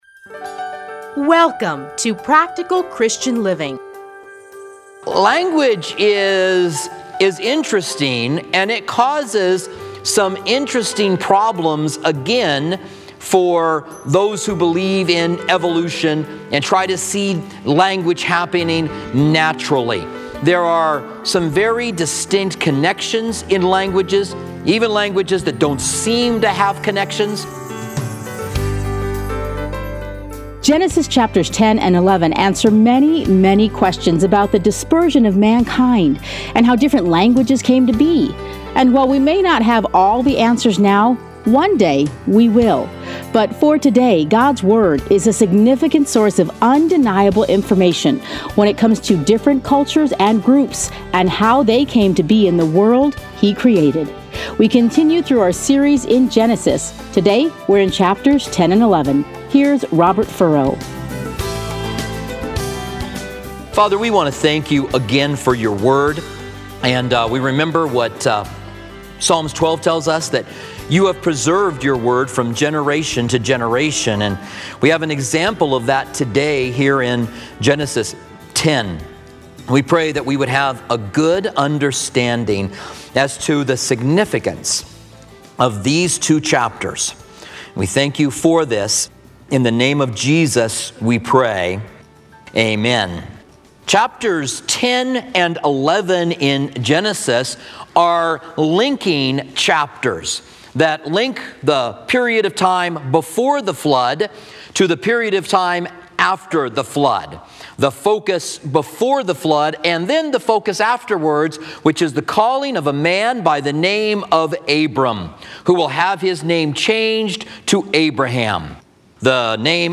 teachings are edited into 30-minute radio programs titled Practical Christian Living. Listen here to a teaching from Genesis.